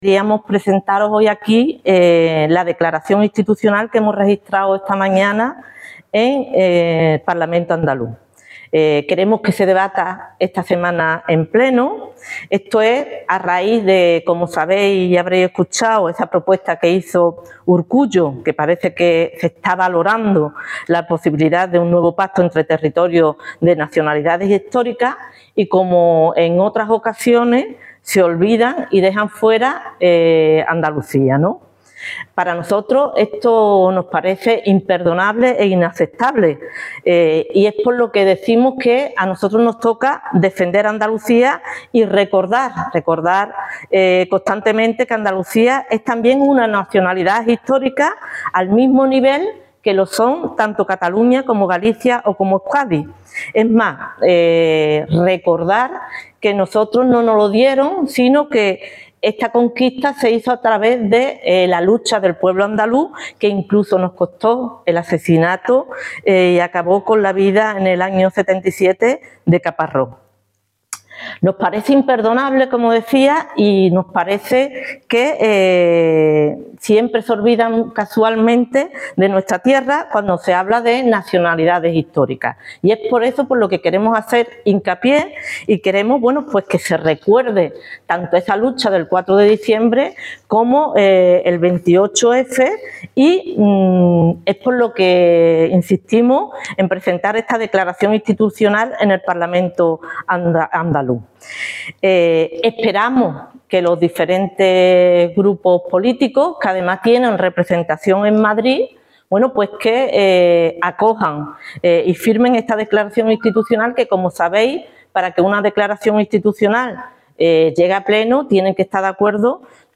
corte-rdp-lunes-declaracion-institucional.mp3